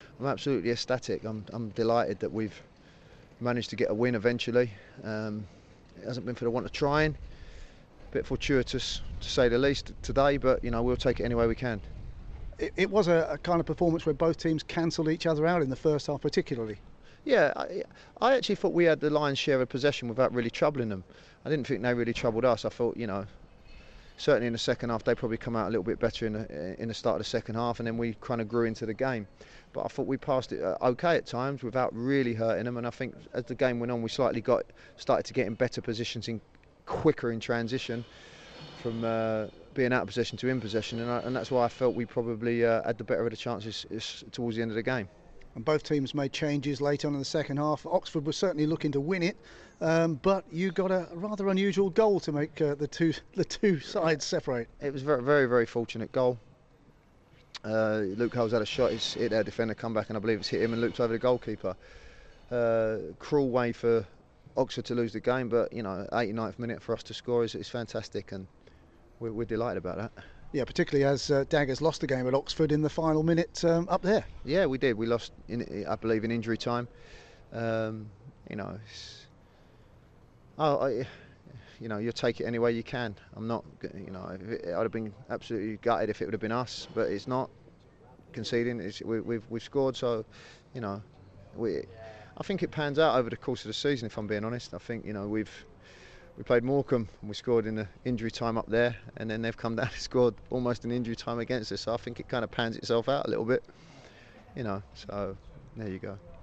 29/03 Post-match